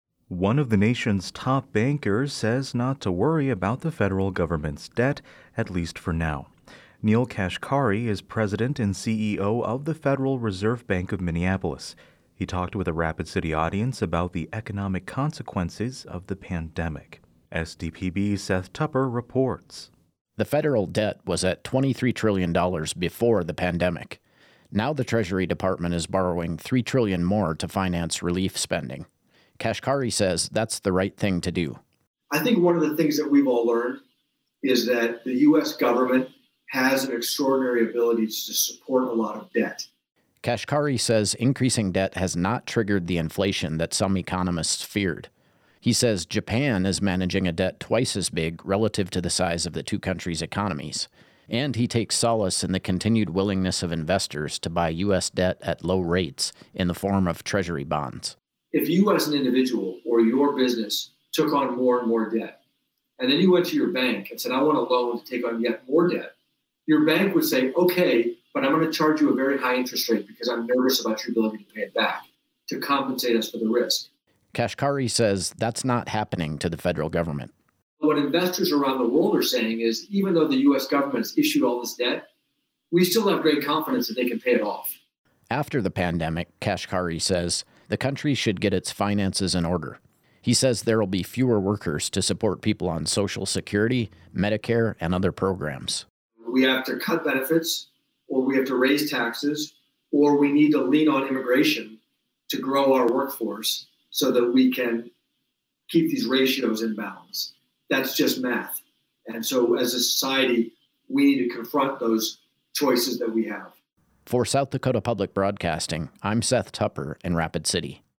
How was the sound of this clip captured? He participated remotely in a live video discussion Tuesday as part of the Morning Fill Up speaker series in Rapid City.